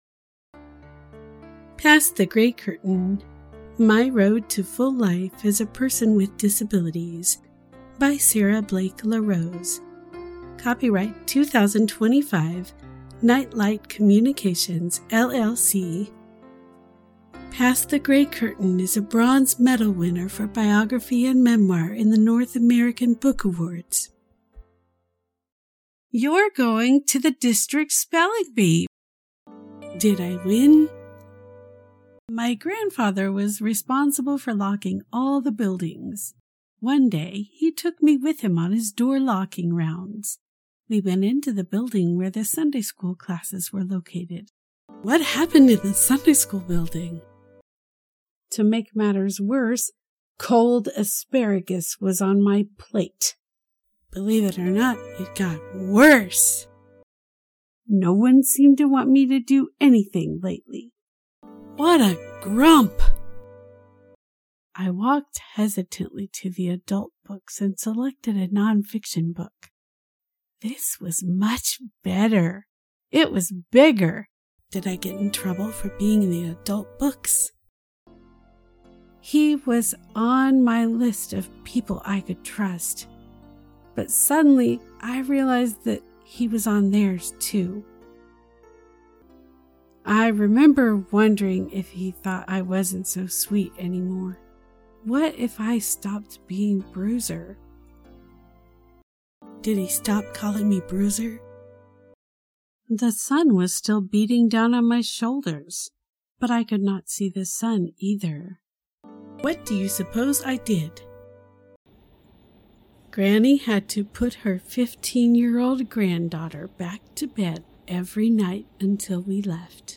Past the Gray Curtain: Audiobook Preview
book-preview.mp3